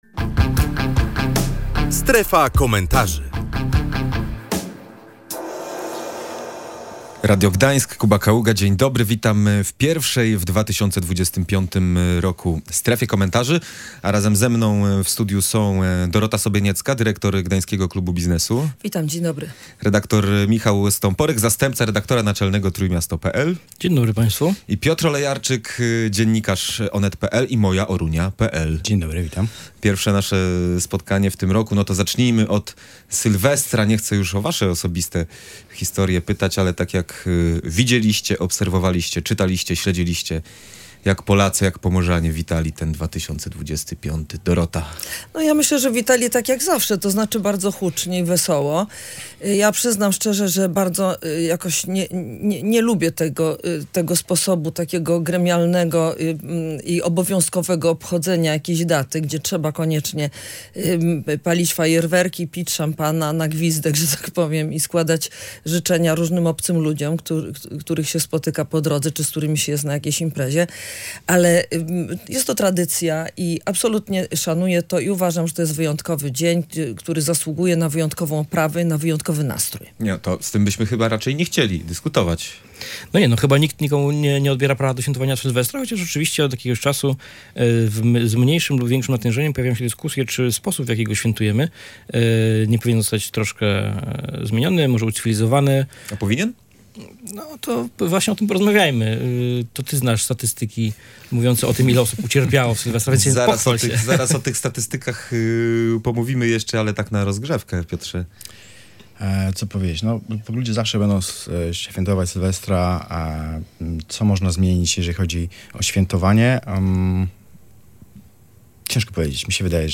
Między innymi o tym rozmawialiśmy w audycji.